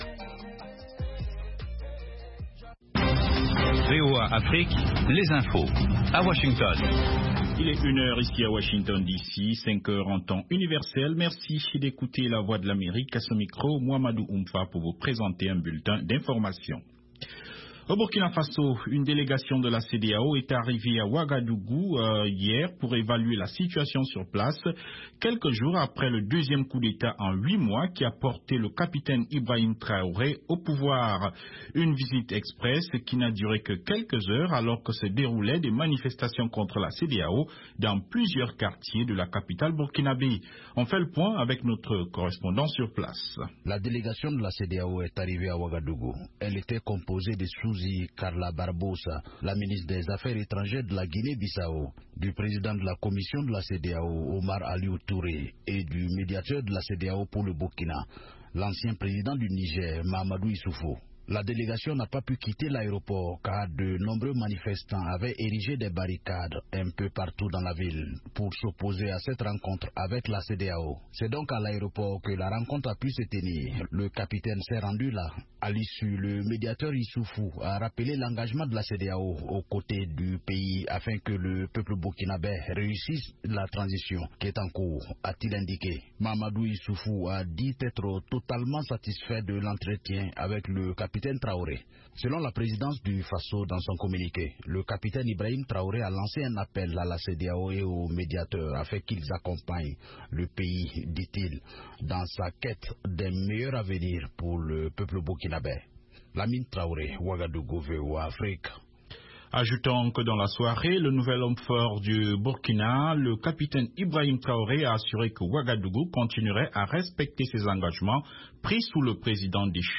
5min Newscast